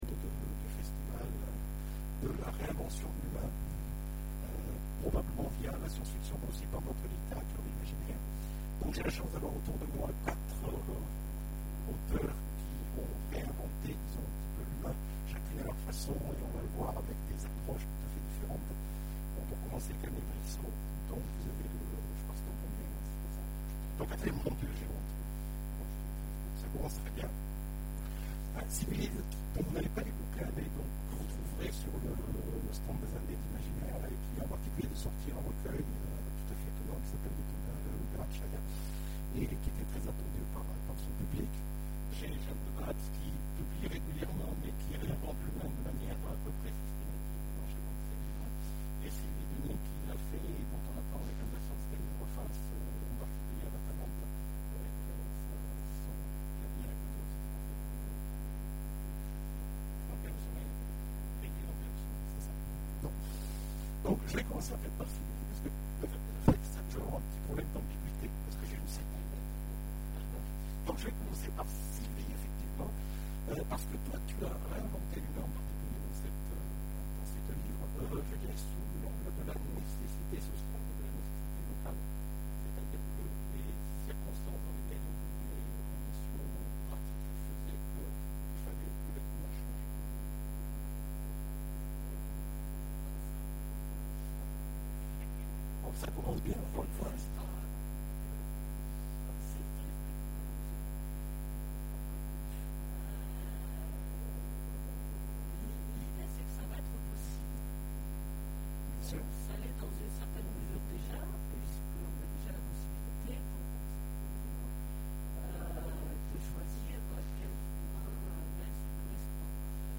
Imaginales 2014 : Conférence Définir l'humain...